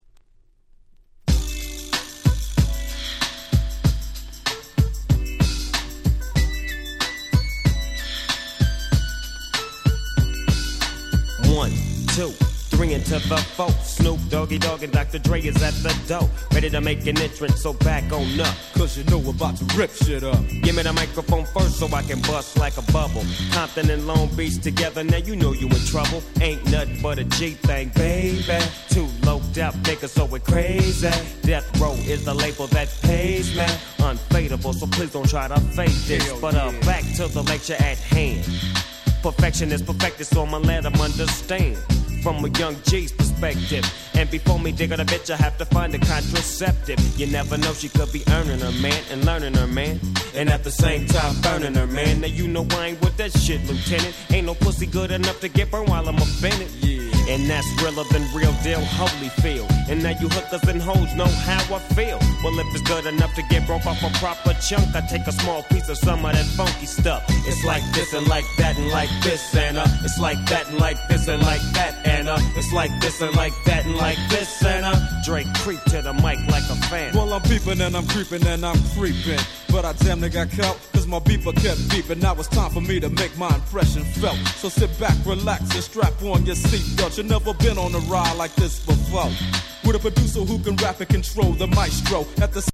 93' Big Hit West Coast Hip Hop.